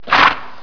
Descarga de Sonidos mp3 Gratis: establo.